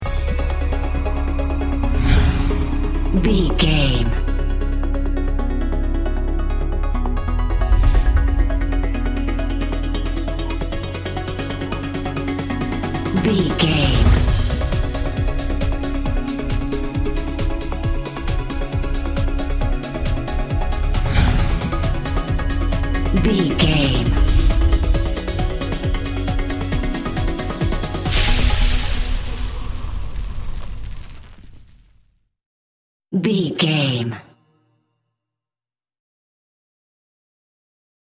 Aeolian/Minor
C#
Fast
groovy
uplifting
futuristic
energetic
bouncy
synthesiser
drums
drum machine
electronic
synth lead
synth bass